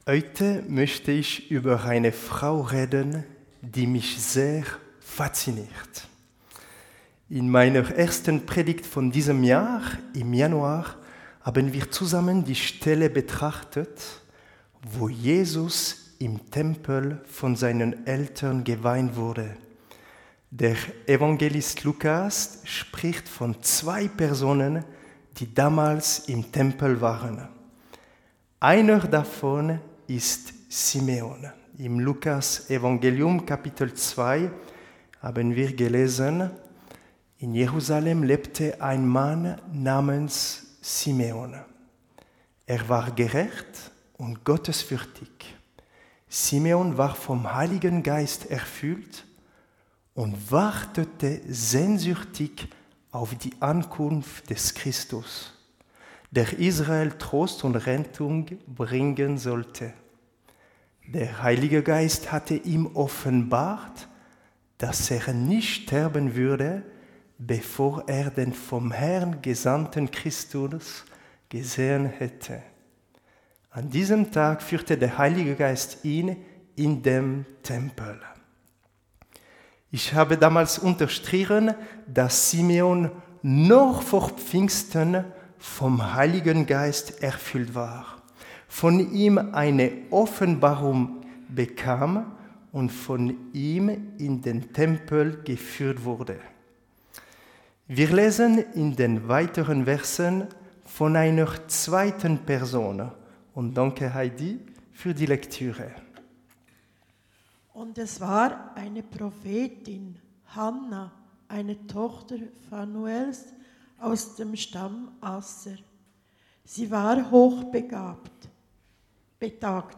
Predigten - Korps Aarau
Von Serien: "Allgemeine Predigten"